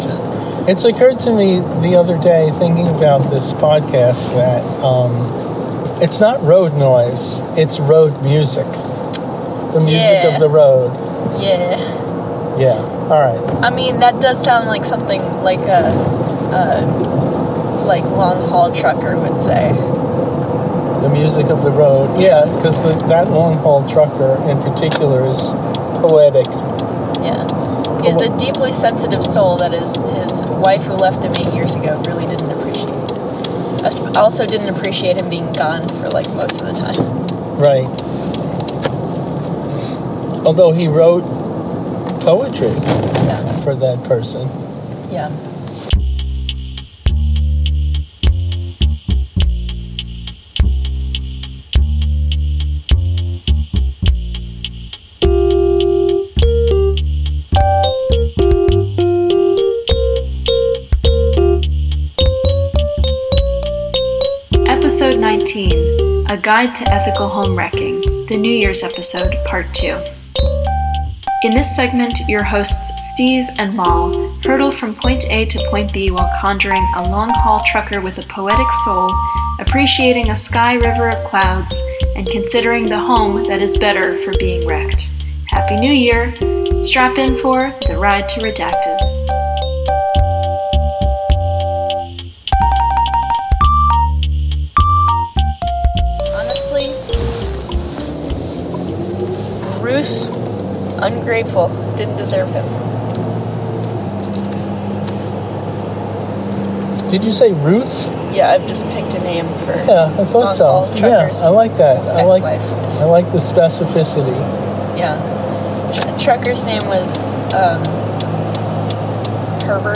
Listeners, the recording of our ride from Point A to Point B on December 21, 2025, was unusually productive. On that ride, your hosts were even more conversationally lively than usual, if you can believe that.